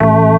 54_06_organ-A.wav